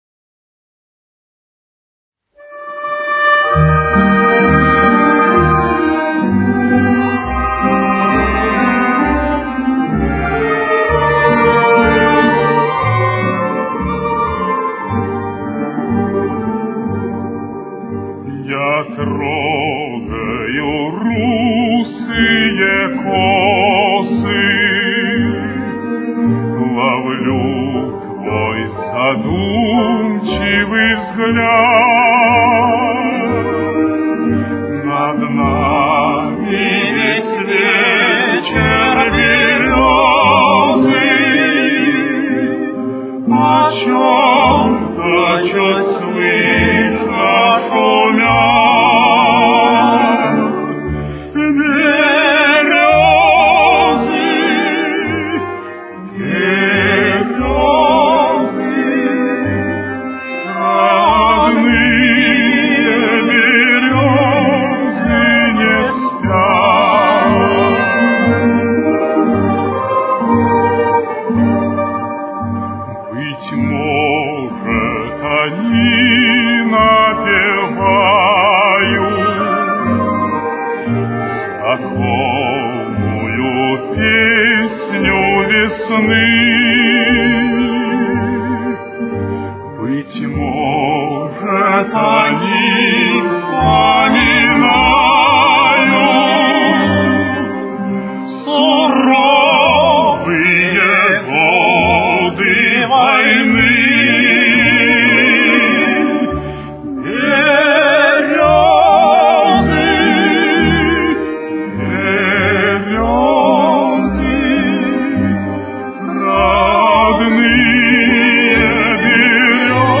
Соль минор. Темп: 60.